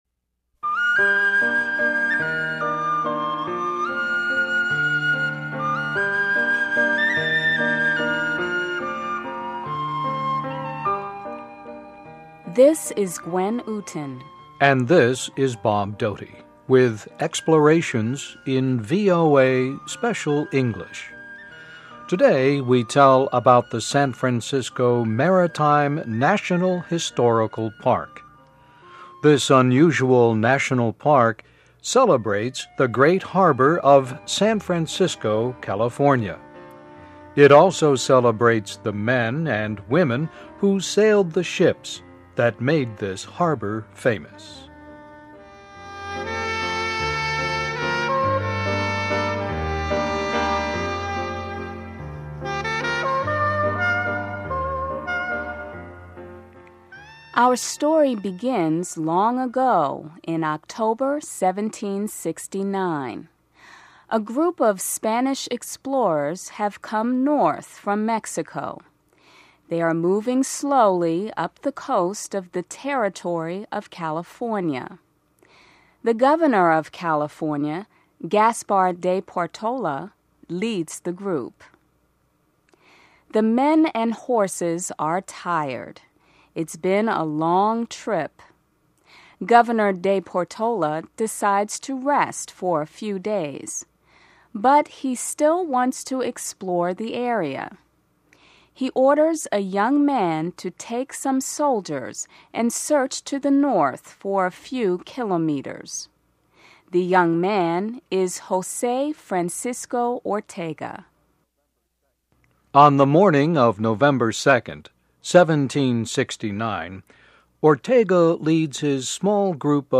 Places: San Francisco Maritime National Historical Park (VOA Special English 2007-09-11)